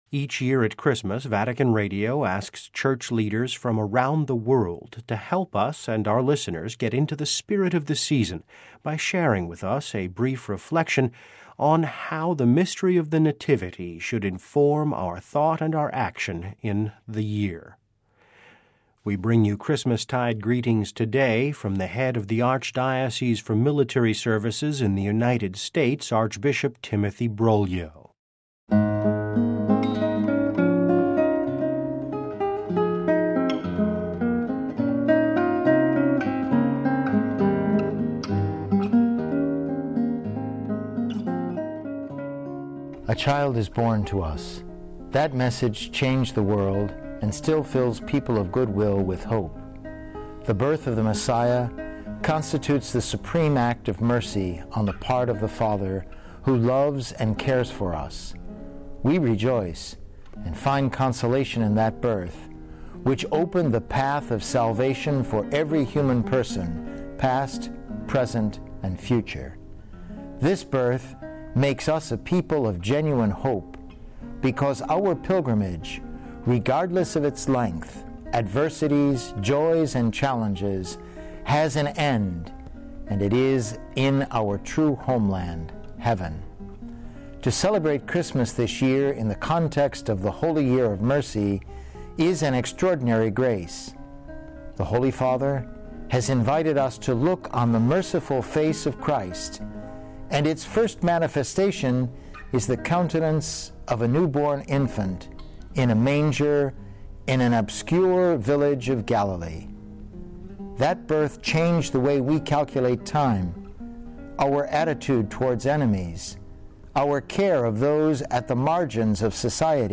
(Vatican Radio) Each year at Christmas, Vatican Radio invites Church leaders from around the world to help our listeners get into the spirit of the season, by sharing a reflection on how the mystery of the Nativity might inform our action throughout the year. Here are Christmastide greetings from Archbishop Timothy Broglio, the Archbishop for the Military Services, USA.